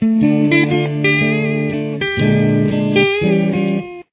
Here it is played with the chords from above :